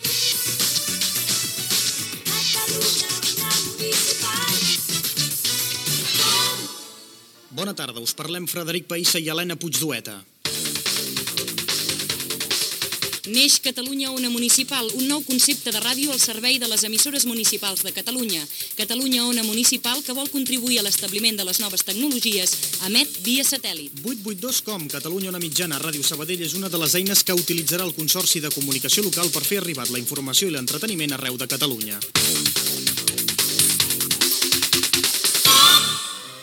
Indicatiu de l'emissora. Notícia del naixement de Catalunya Ona Mitjana i Catalunya Ona Municipal
Informatiu